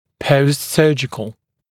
[ˌpəustˈsɜːʤɪkl][ˌпоустˈсё:джикл]происходящий после хирургической операции или лечения